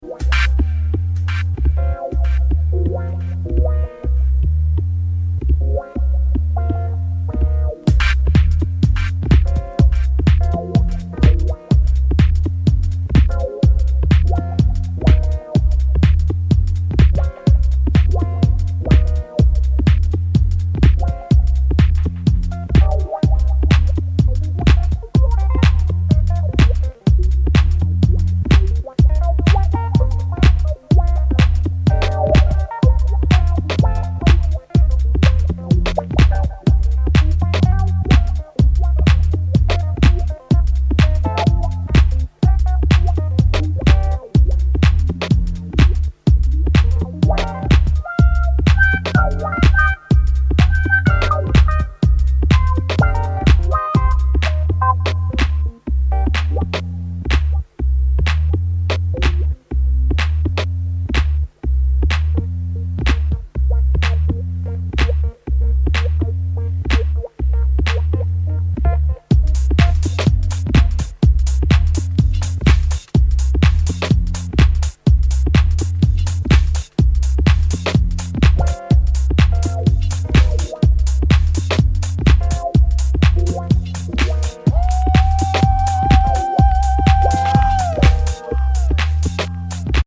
deep house
soulful vocals, jazzy harmonies, funky bass lines